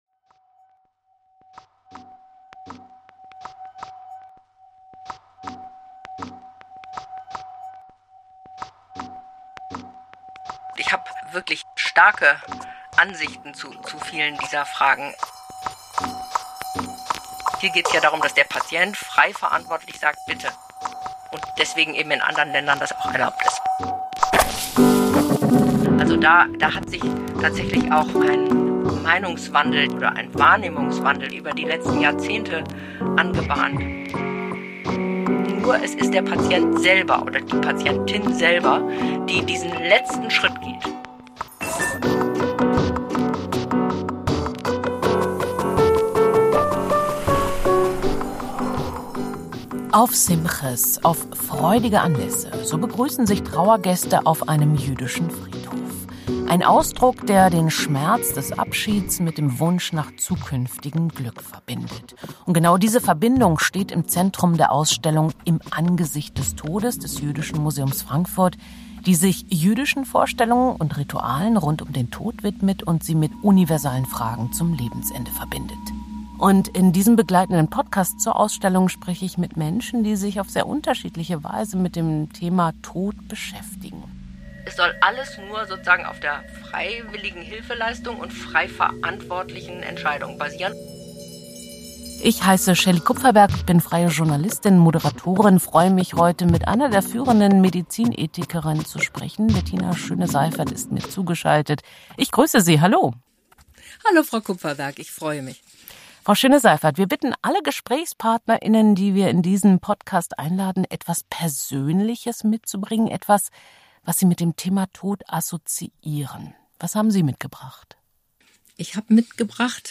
Ein Gespräch über Ethik, Recht, Realität und die Frage, wie viel Freiheit wir am Ende wirklich wollen.